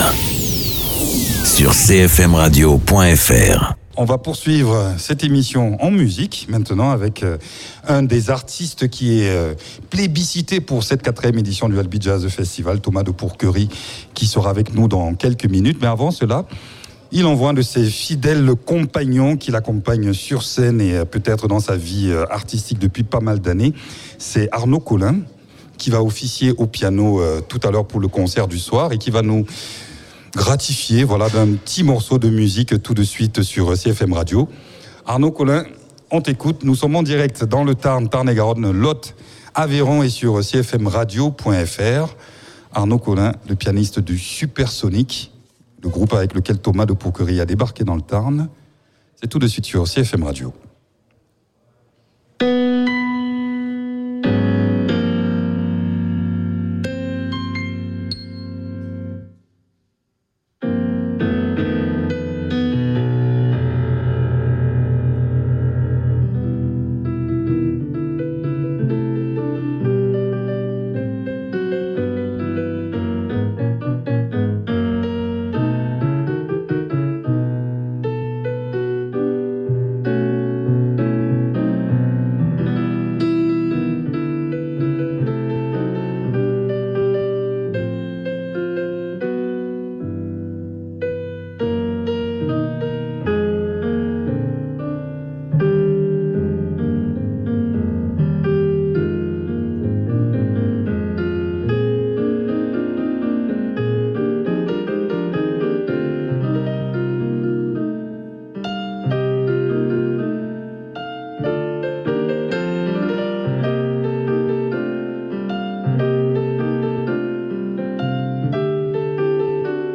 Invité(s) : Thomas de Pourquery, comédien, auteur-compositeur.